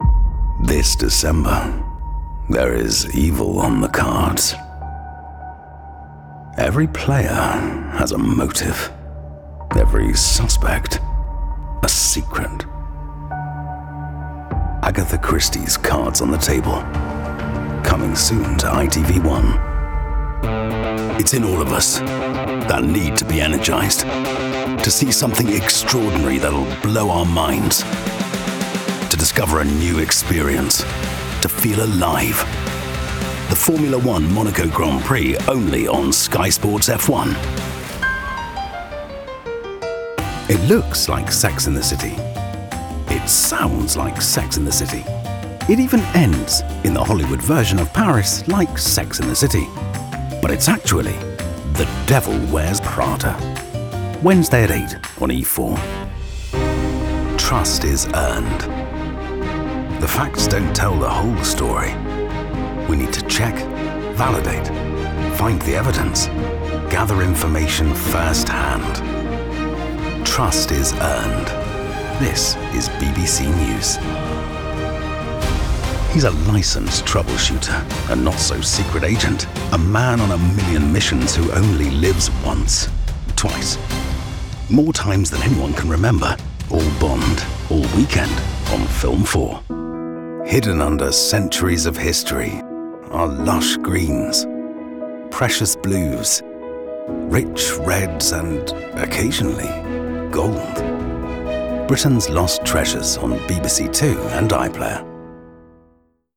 Promo Showreel
Male
British RP
Neutral British
Authoritative
Down To Earth
Friendly
Gravelly
Gravitas